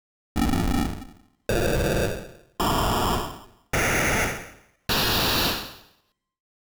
Nicht ganz, wenn man beim SID z.B. die Noise in verschiedenen Tonhöhen spielt, klingt die immer anders, nach unten mehr wie bitcrushing, kann man schlecht erklären deswegen hier ein Soundbeispiel, wenn man jetzt die Tonhöhe schnell moduliert, klingt's eben so wie beim originalen, das können nicht viele Synth von Haus aus...